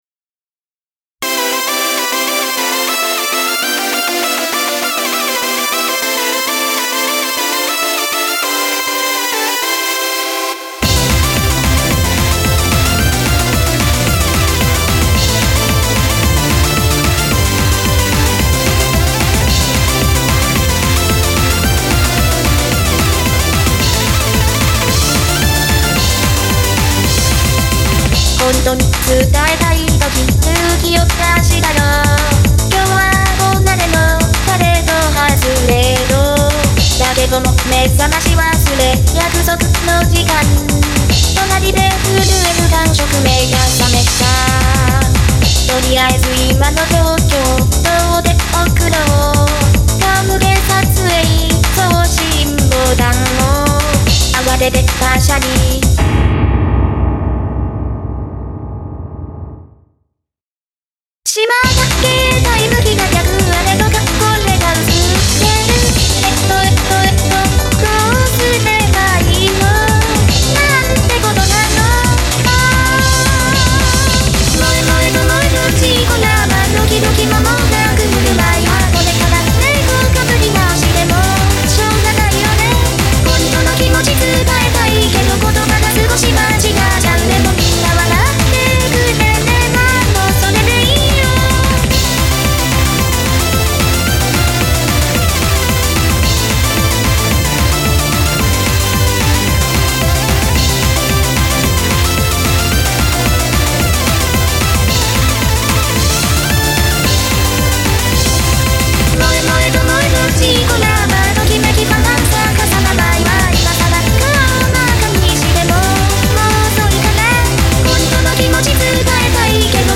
ランカの中の人・中島愛さんの声をもとにしたVOCALOIDです。
特になんの工夫もない普通のセルフカバーなので これ単体での動画は作らない予定です。